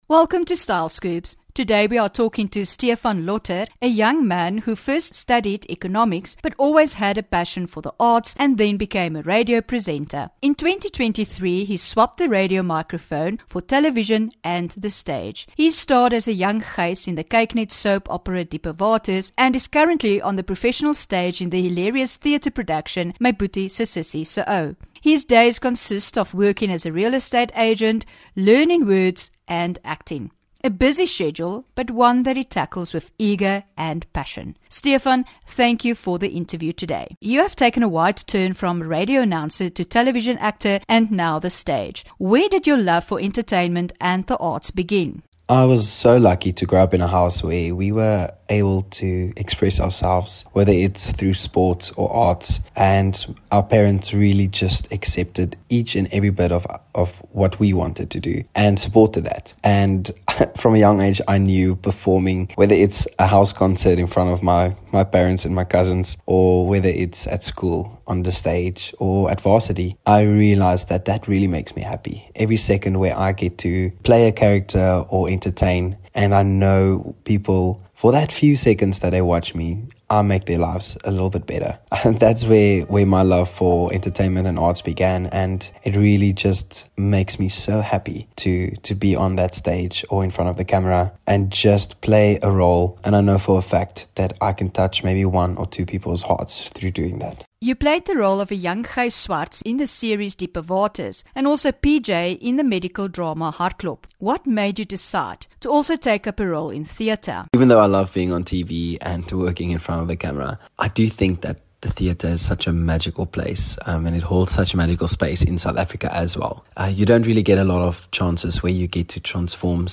10 Feb INTERVIEW